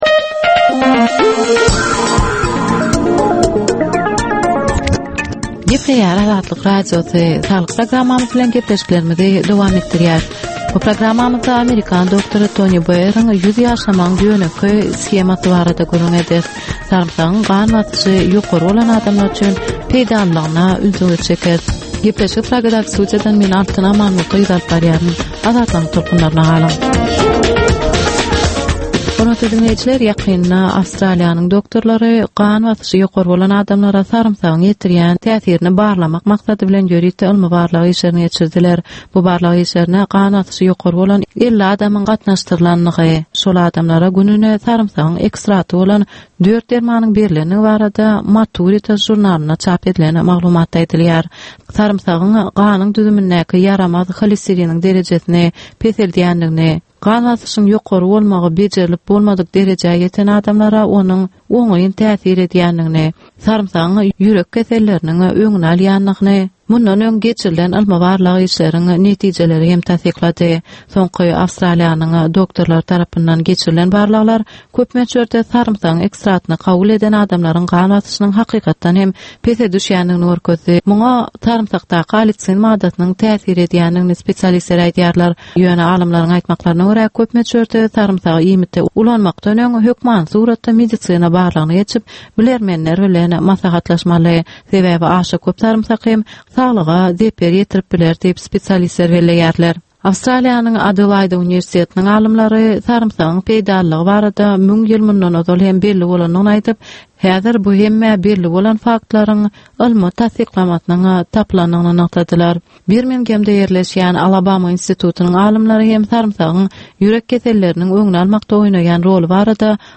Ynsan saglygyny gorap saklamak bilen baglanyşykly maglumatlar, täzelikler, wakalar, meseleler, problemalar we çözgütler barada ýörite gepleşik.